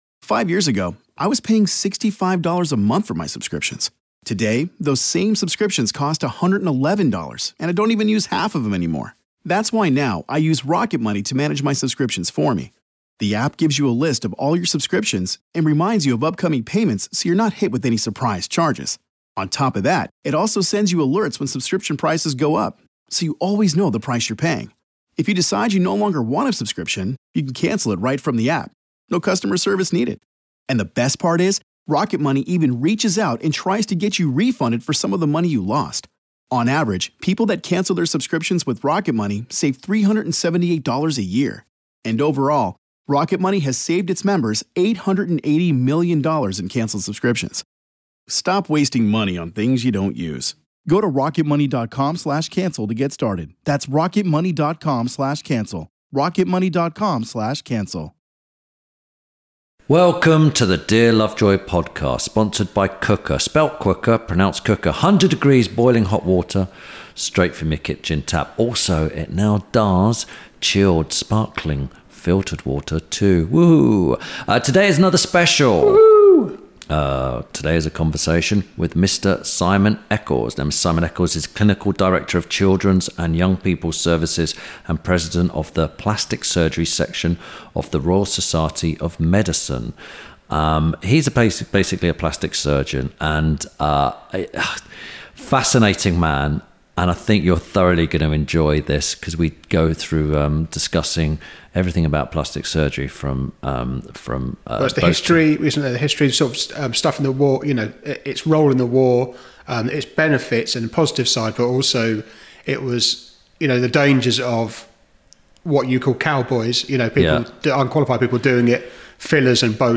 – INTERVIEW SPECIAL